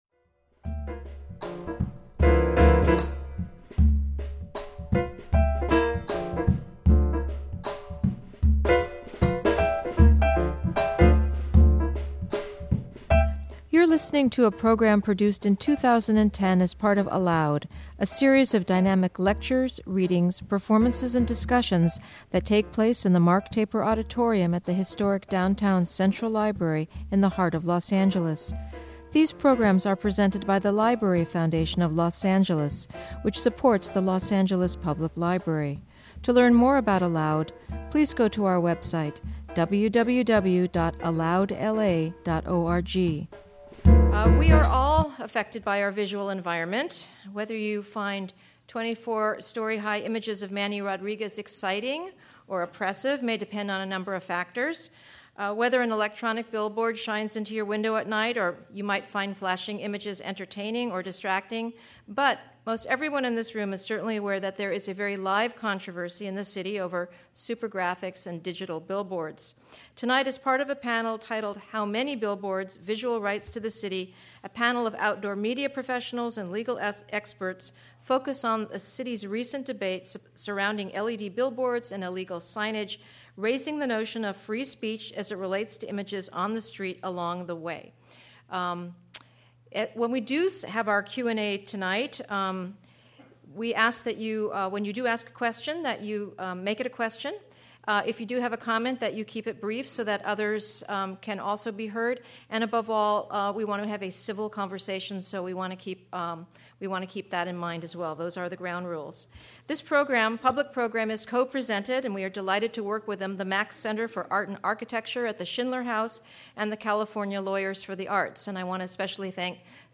A panel of outdoor media professionals and legal experts focus on the city's recent debate surrounding LED billboards and illegal signage, raising the notion of free speech as it relates to images on the street along the way.